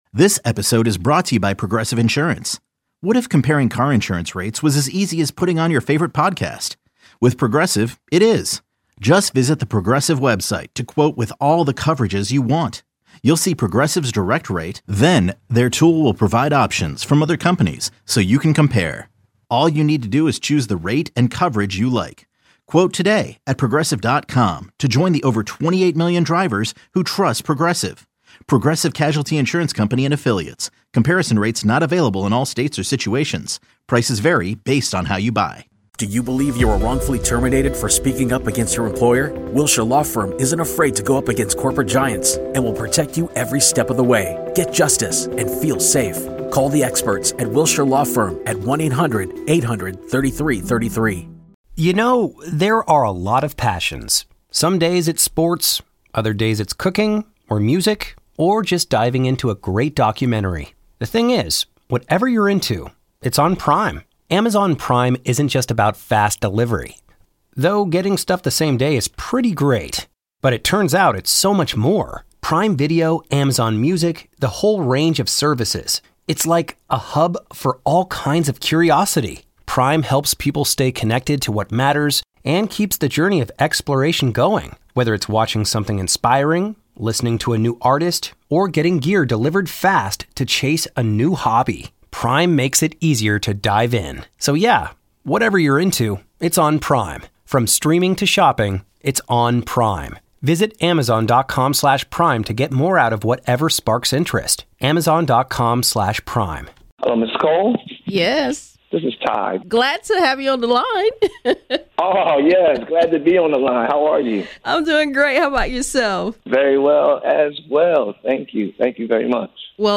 From the pulpit of 1510 Big WEAL Gospel, the best inspiration from God's Word.